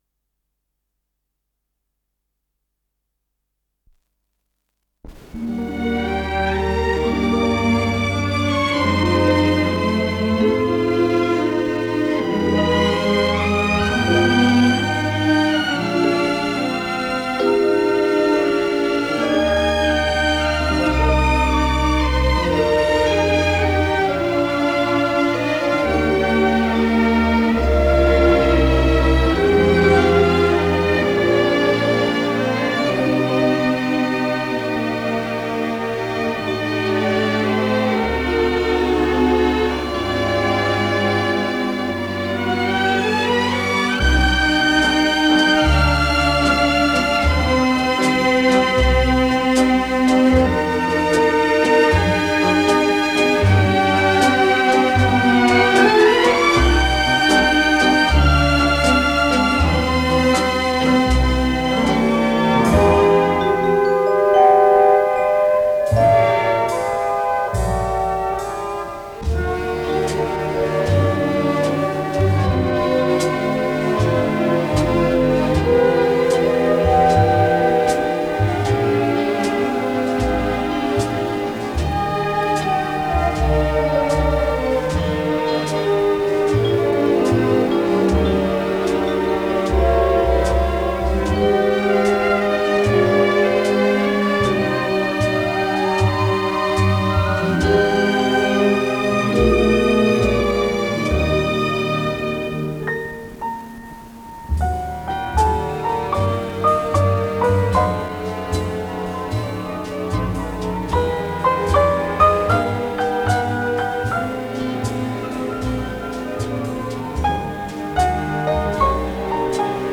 ноктюрн
фортепиано